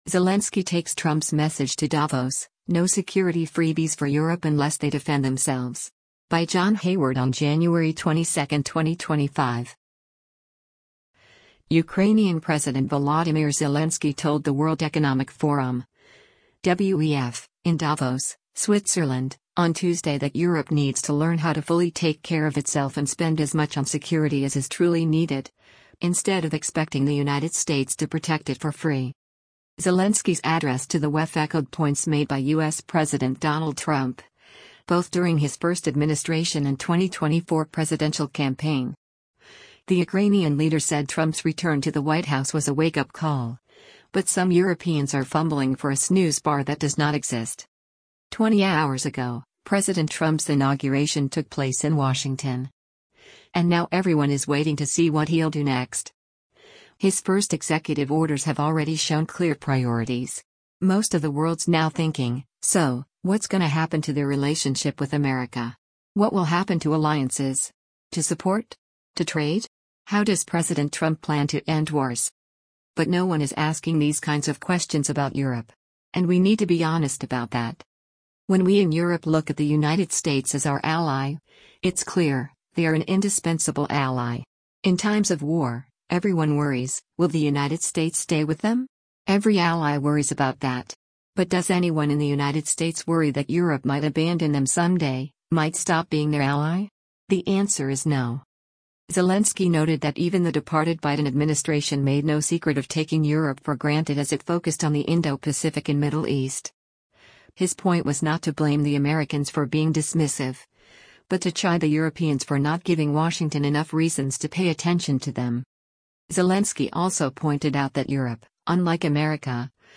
Ukrainian President Volodymyr Zelensky told the World Economic Forum (WEF) in Davos, Switzerland, on Tuesday that “Europe needs to learn how to fully take care of itself” and “spend as much on security as is truly needed,” instead of expecting the United States to protect it for free.